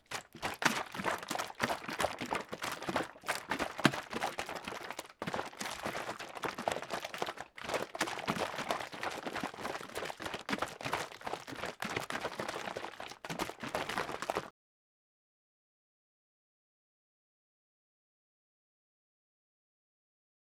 SoundFX